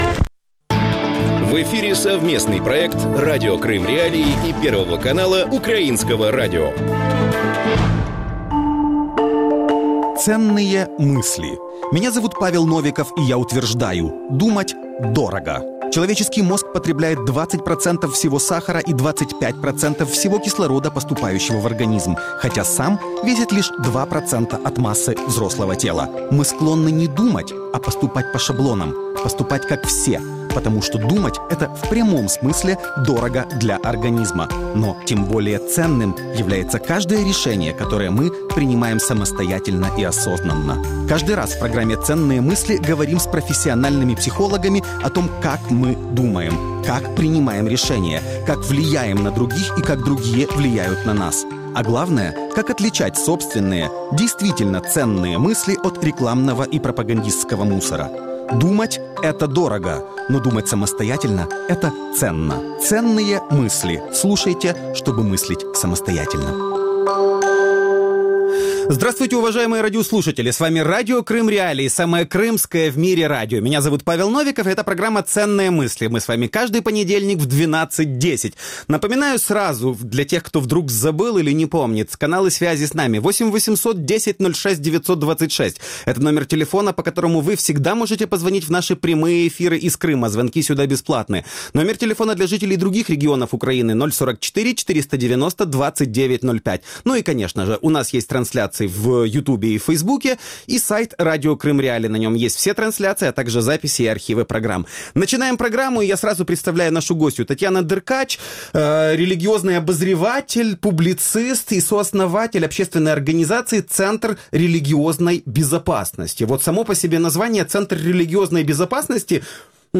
Как церковь способствовала захвату Крыма и началу войны на Востоке Украины? Как РФ пытается противодействовать получению Томоса об автокефалии украинской церкви? Об этом в проекте «Ценные мысли» Радио Крым.Реалии с 12:10 до 12:40.